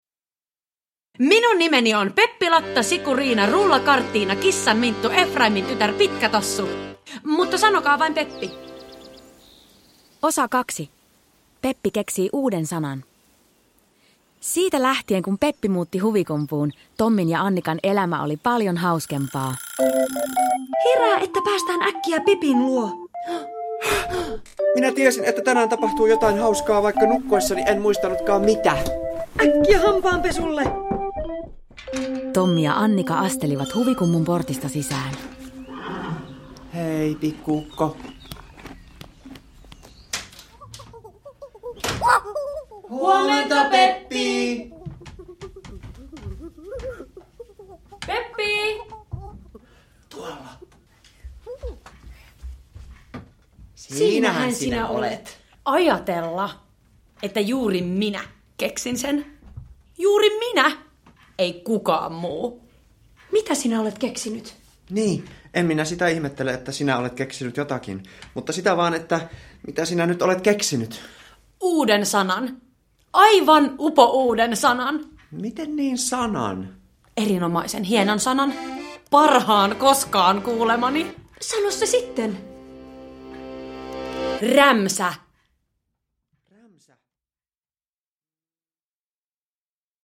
Kaikki on vinksin vonksin näissä iloisissa kuunnelmissa!
Kaikille tuttu Peppi Pitkätossu ilahduttaa nyt eläväisinä ja hauskoina kuunnelmina.